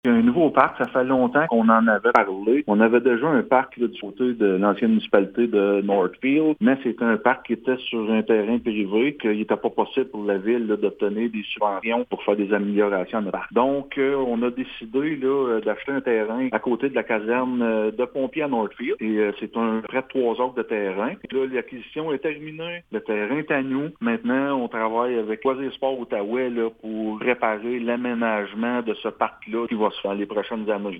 Mathieu Caron, maire de la Ville de Gracefield, précise qu’il s’agira d’un parc unique dont l’aménagement sera différent des autres parcs existants :